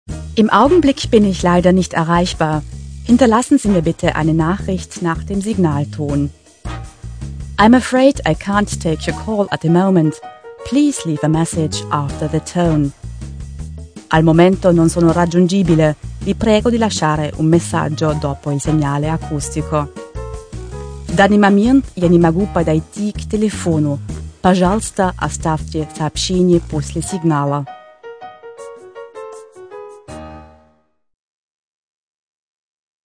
• AUDIOPROBE: Professionelle Mobilboxansage
professionelle-mobilboxansage.mp3